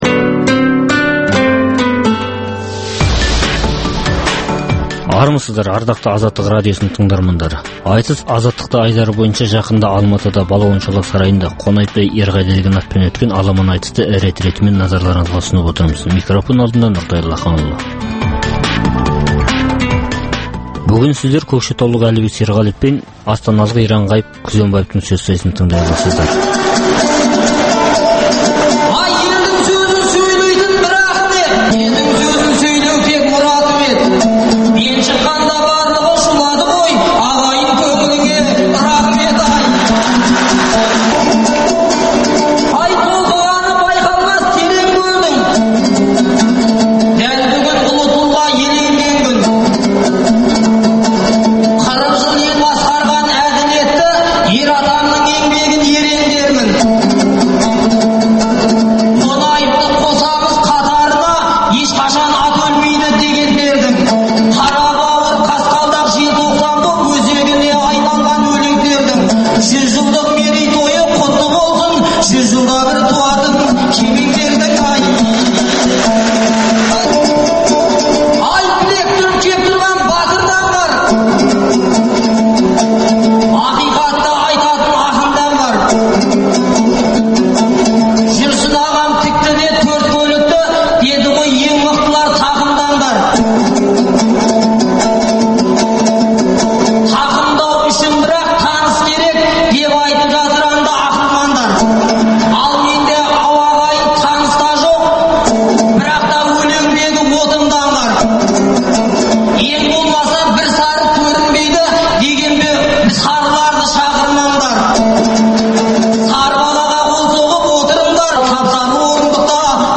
Алматыда өткен ақындар айтысына